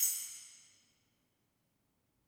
JJPercussion (14).wav